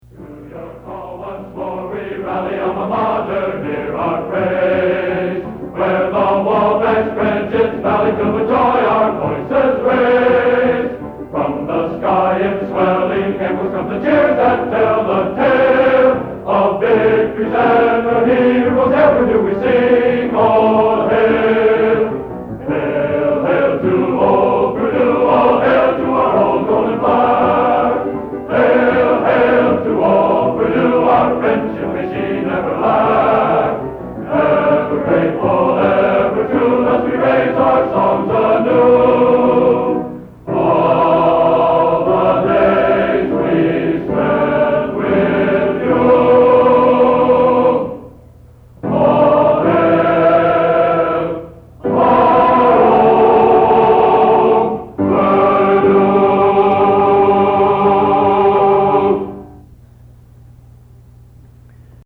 Genre: Collegiate | Type: